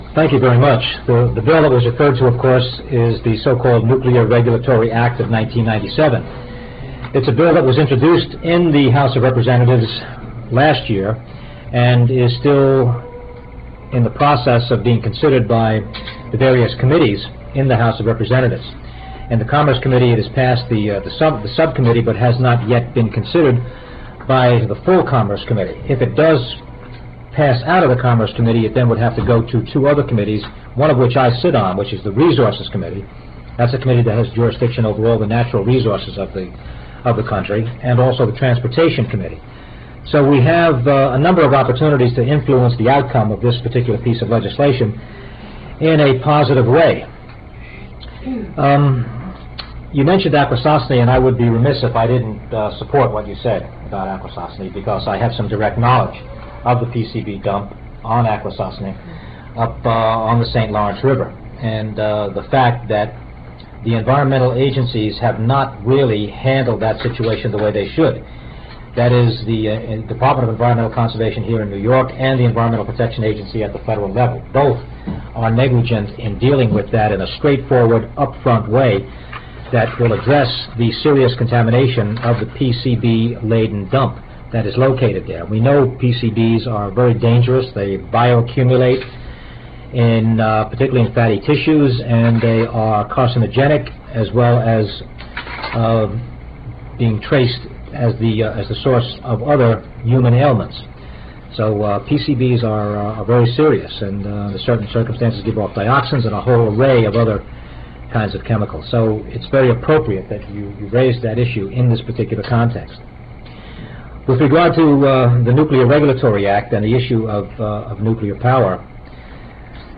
lifeblood: bootlegs: 1997-09-15: honor the earth press conference - poughkeepsie, new york
03. press conference - maurice hinchey (6:21)